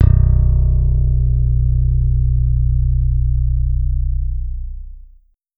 KAGI FRET -L.wav